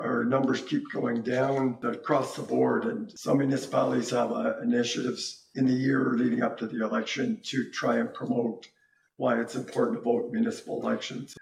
Councillor Peter McKenna’s happy with the vision.